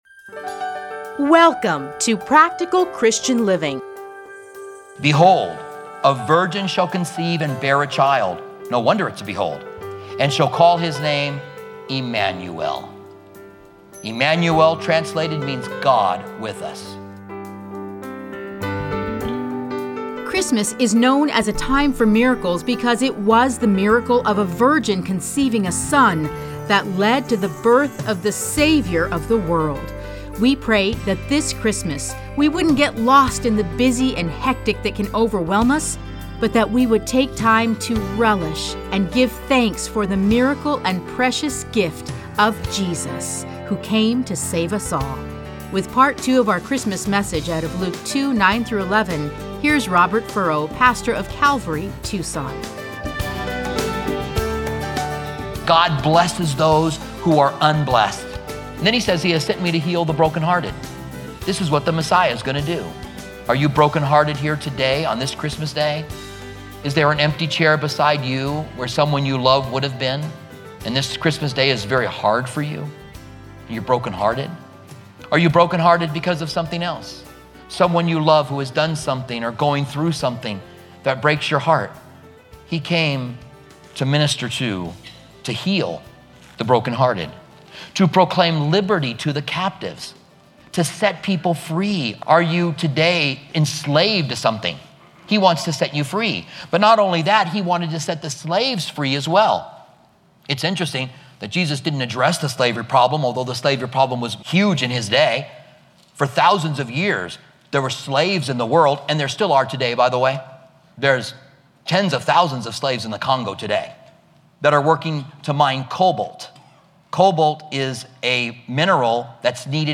Listen to a teaching from Luke 1-2.